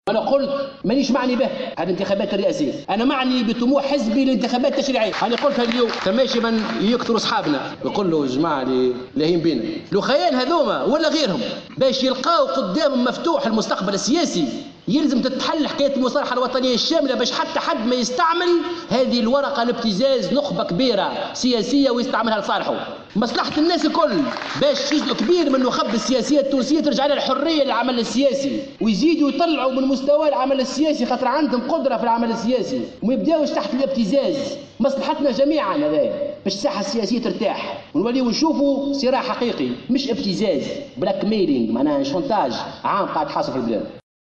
وأضاف خلال ندوة نظمتها حركة المشروع اليوم السبت حول المصالحة الوطنية الشاملة، أن المصالحة الشاملة شرط بناء الوحدة الوطنية، وهي الكفيلة بإرجاع الحرية لنخبة كبيرة من التونسيين في العمل السياسي وعودة الصراع السياسي الحقيقي، وللقضاء على الابتزاز داخل الساحة السياسية.